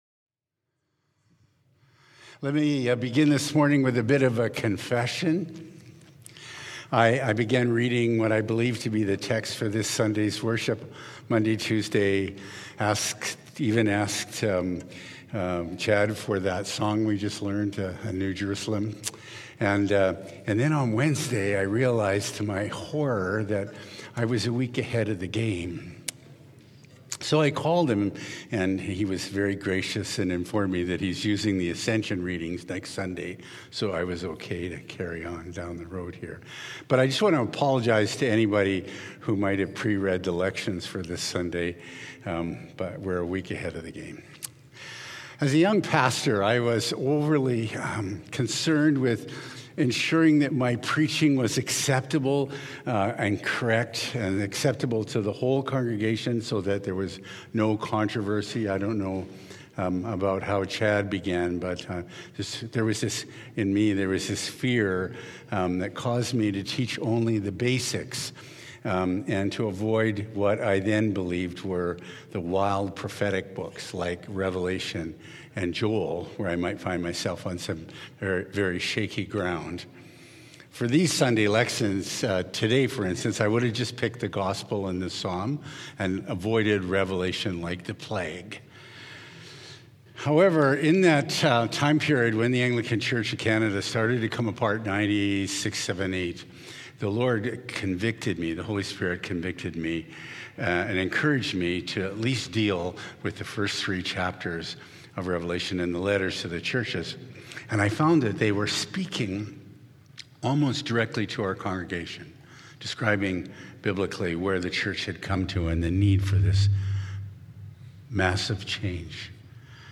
Sermons | Emmaus Road Anglican Church
Current Sermon